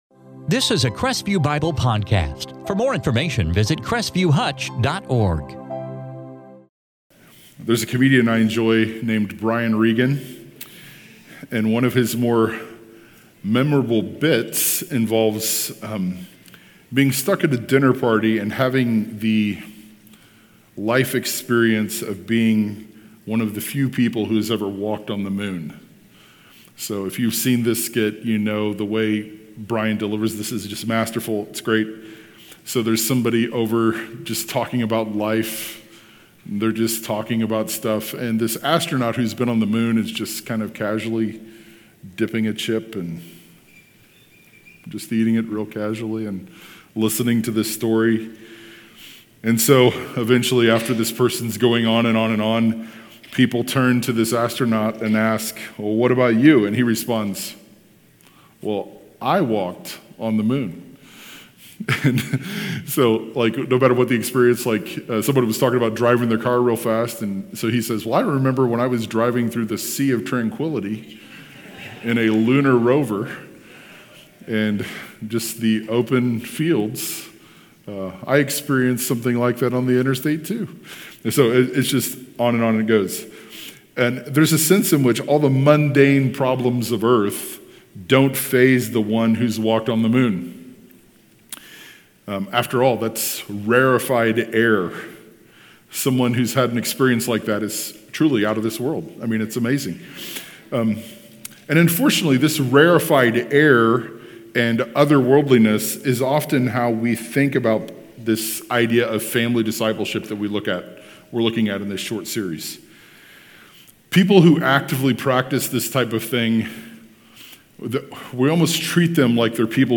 In this sermon from Deuteronomy 6:4-13